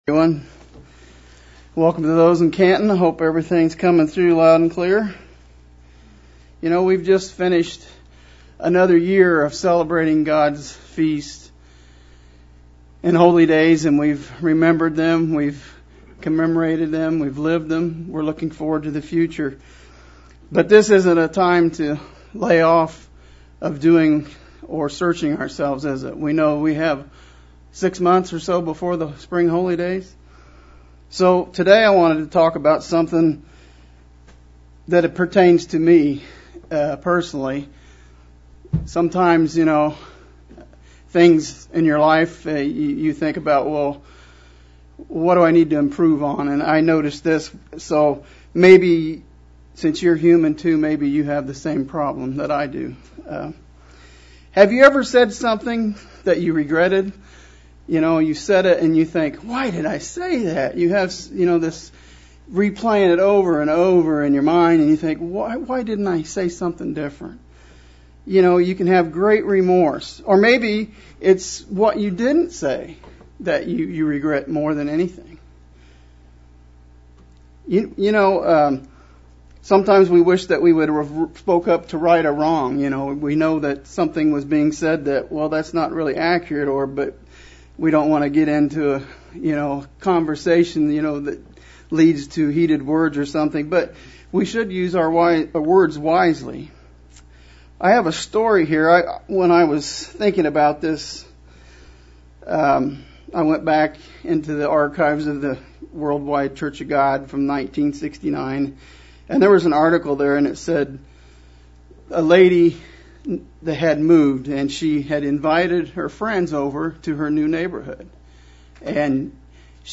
A wonderful sermon on the power of the tongue and the poison it can spread. Examples from the Bible on the pitfalls of the tongue as well as ways we can use this instrument in a GODLY manner.